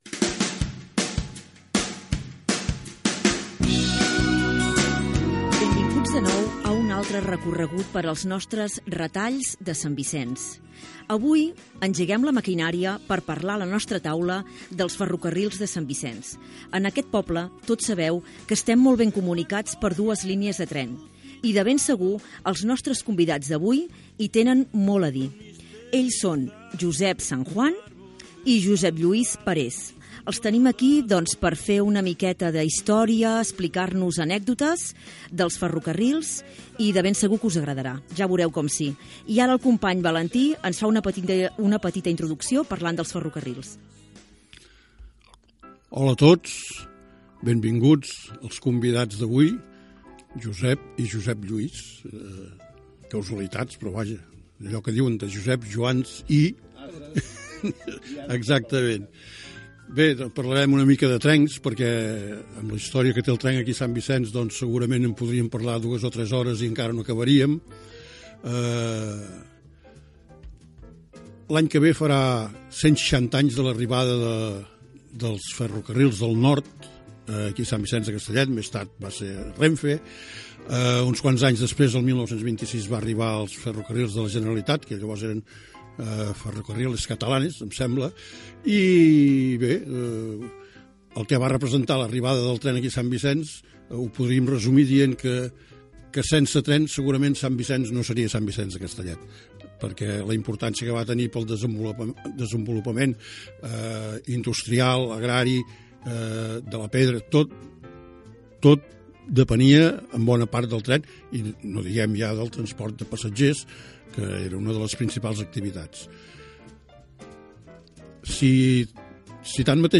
Benvinguda, presentació i fragment d'una tertúlia sobre trens i ferrocarrils a Sant Vicenç de Castellet.
Divulgació